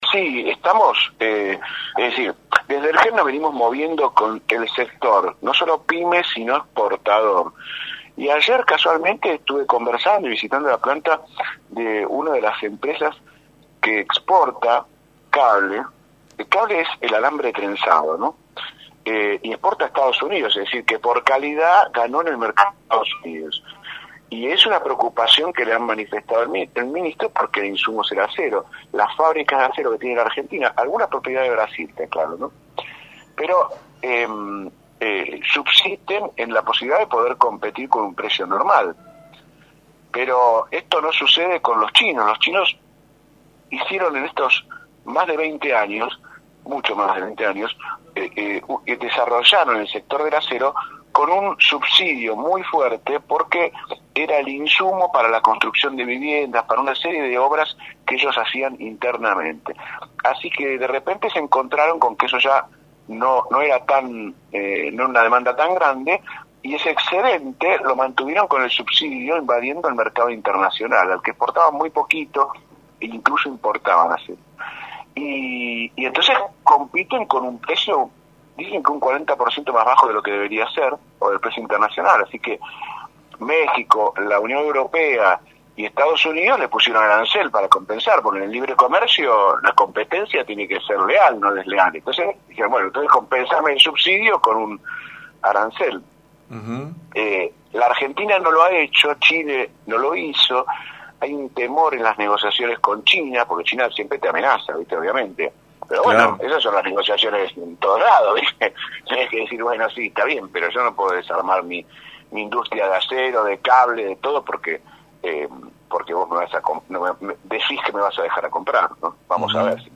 Sergio Abrevaya, Presidente del partido GEN, diálogo en exclusiva para Tucumán en “6AM”, y analizó la situación industrial y política del país.